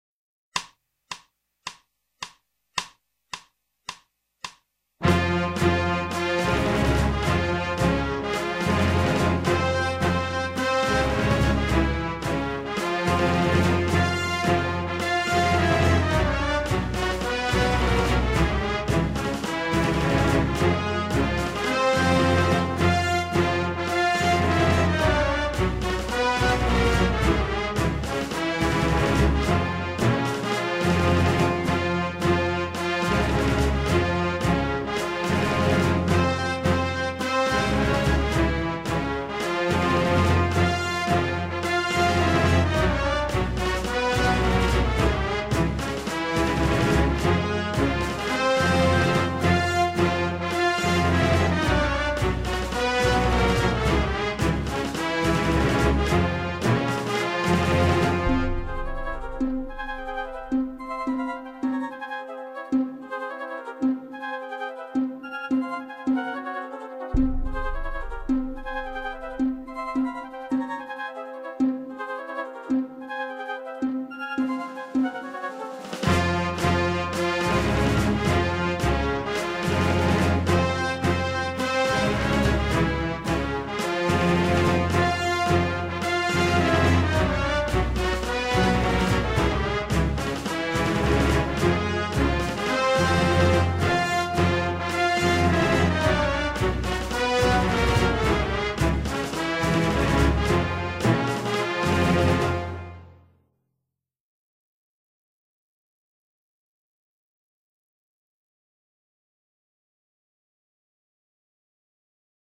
Performance Tempo
Piano Only